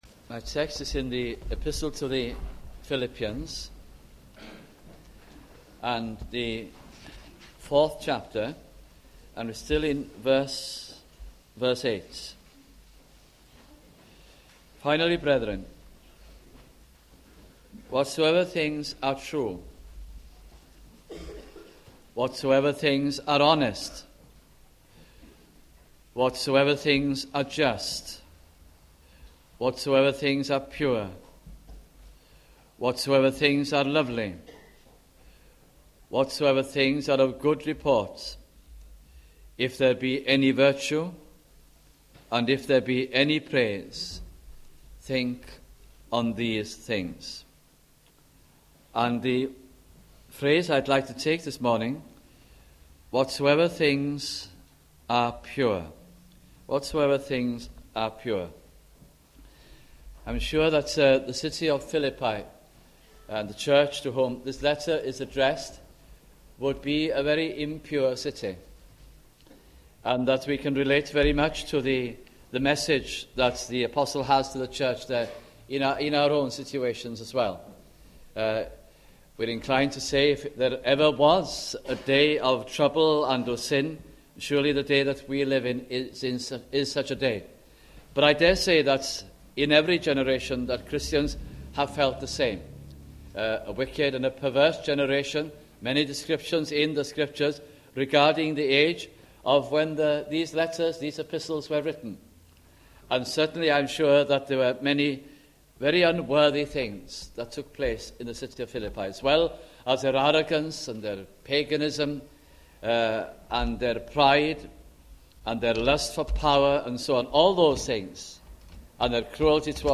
» Philippians Series 1989-90 » sunday morning messages